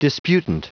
Prononciation du mot disputant en anglais (fichier audio)
Prononciation du mot : disputant